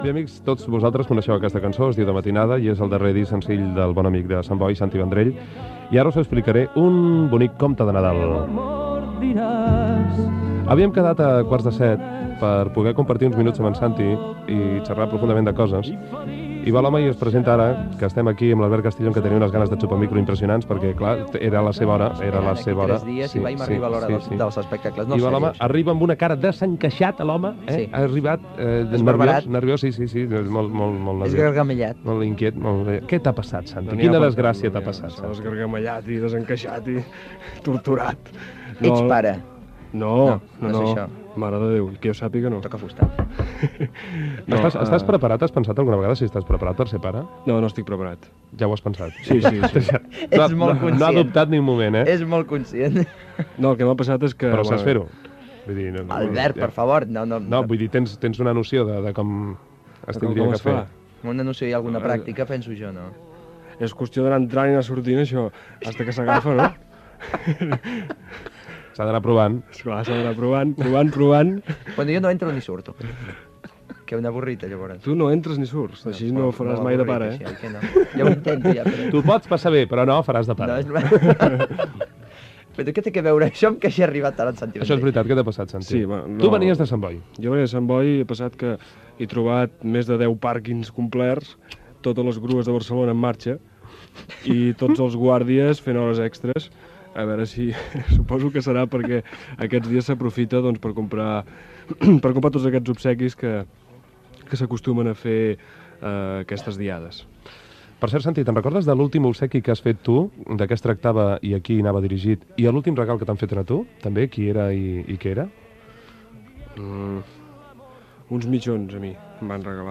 Musical
Programa de llista d`èxits musicals i entrevistes a cantants.